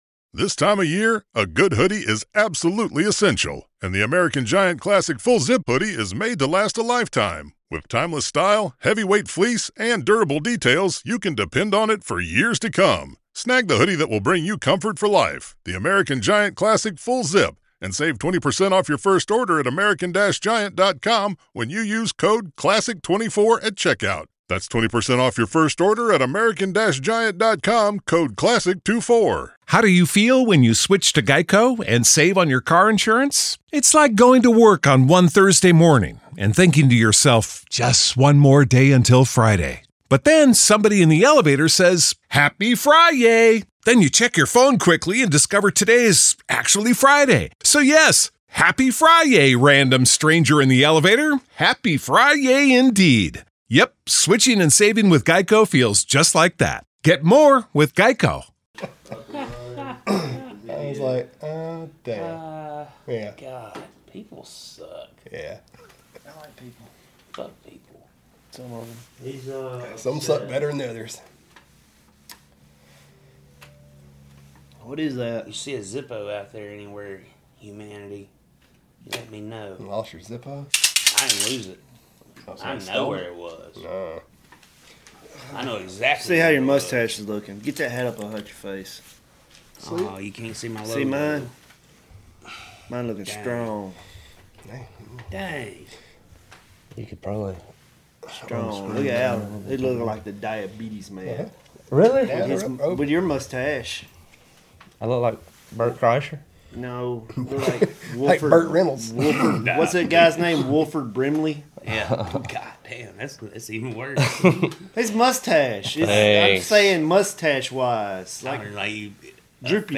candid conversations with creatives about their latest endeavors and interesting stories from their past